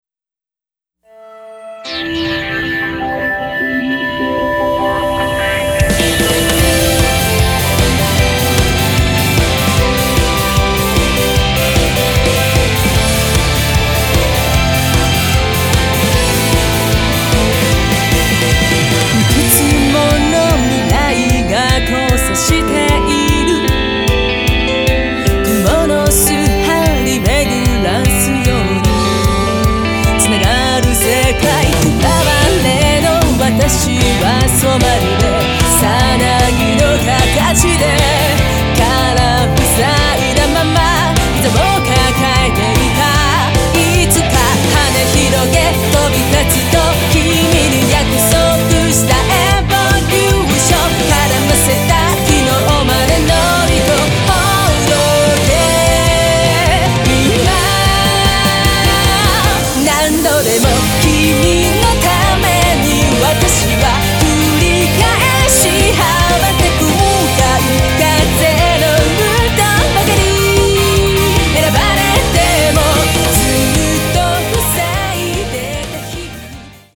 クロスフェードデモ
極みのプラトニックポップ＆ファンタジックロック東方フルボーカルアルバムが登場！
Guitar
Piano & strings